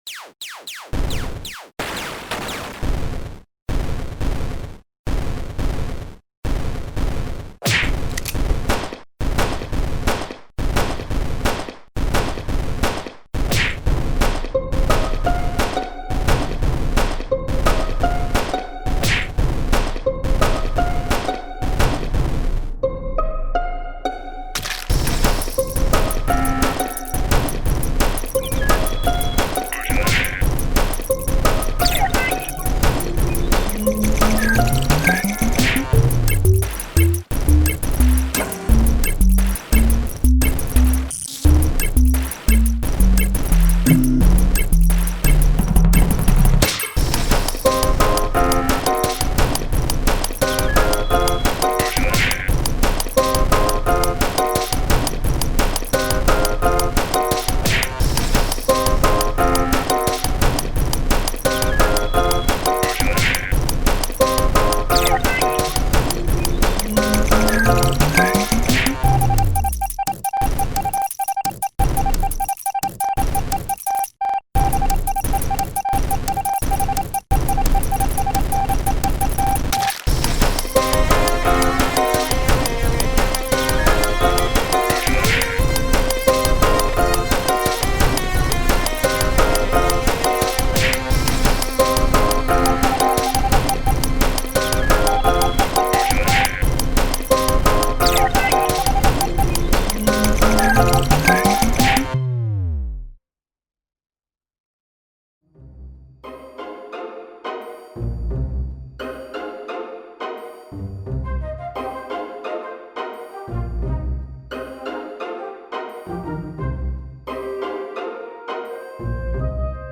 KEgqicK8hum_a-song-using-among-us-sounds.mp3